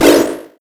menu sounds
confirm.wav